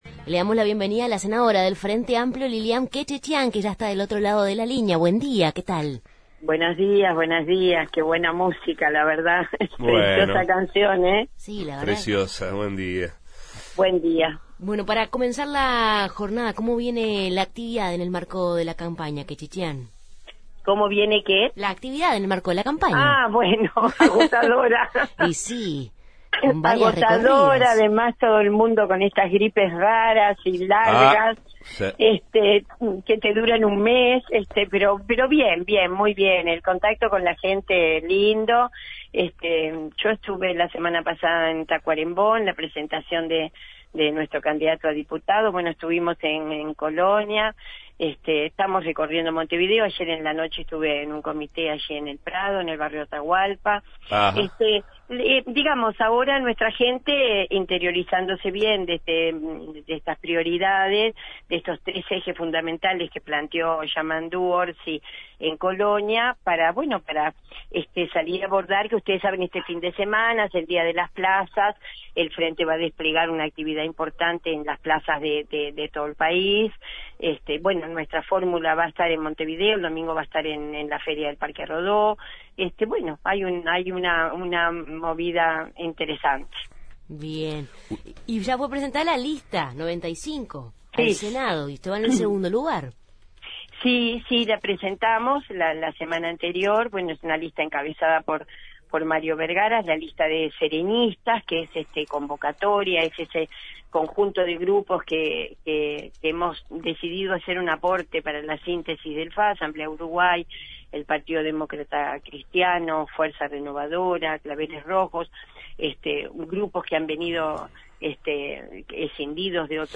Entrevista a la senadora frenteamplista Liliam Kechichian